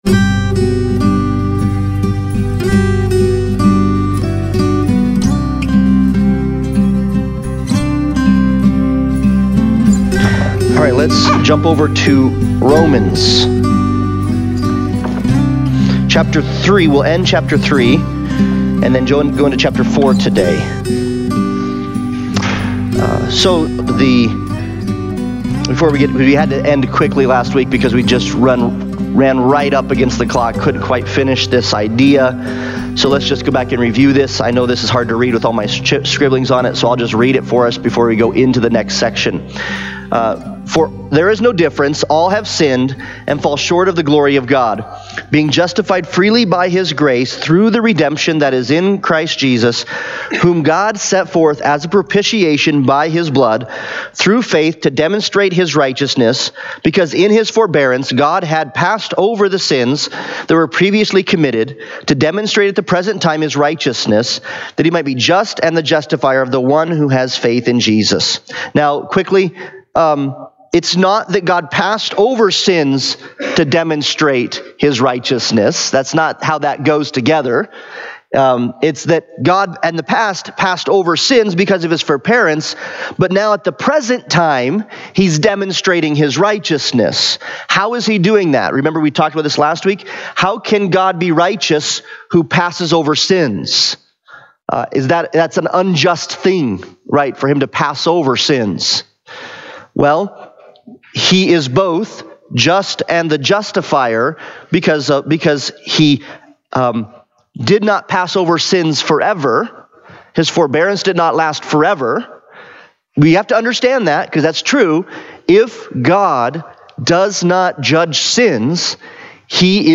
Romans Analysis Passage: Romans 3:23-4:8 Service Type: Sunday Bible Study « Immanuel